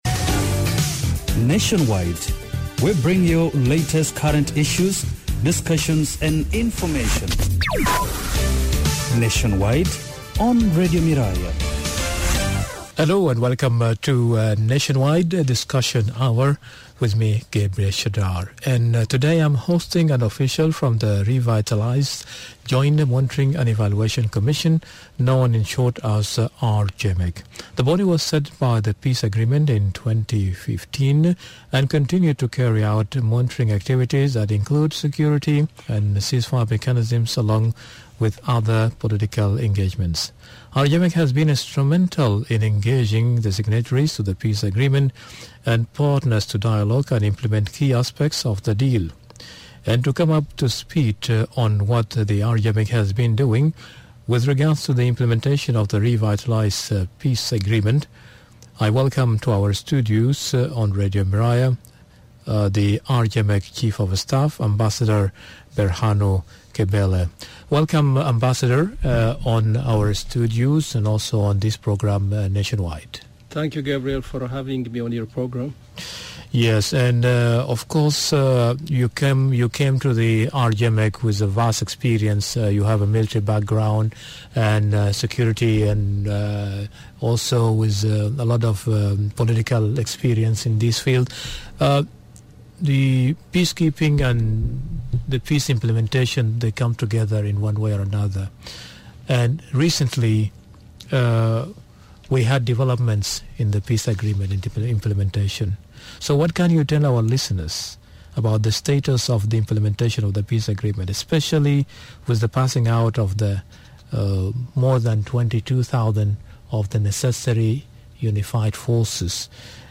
In this Nationwide discussion we are hosting the Chief of Staff for Revitalized Joint Monitoring and Evaluation Commission, Ambassador Berhanu Kebel.